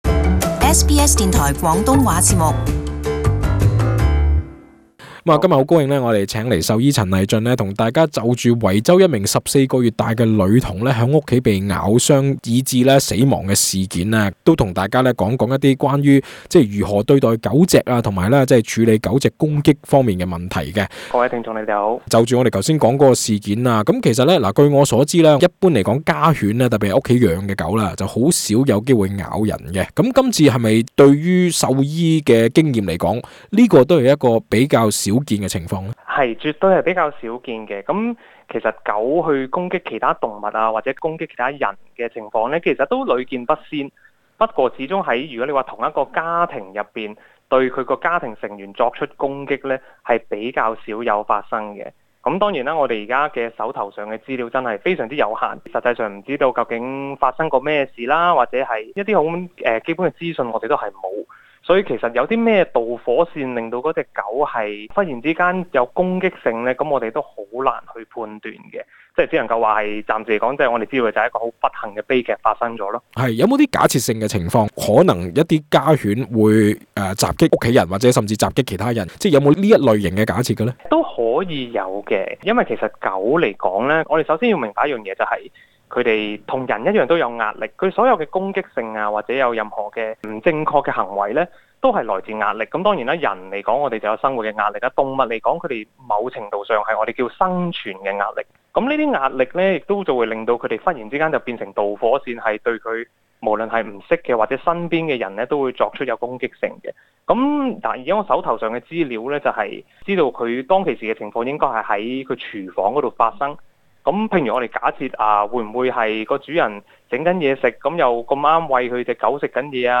【特別專訪】如何避免受犬隻襲擊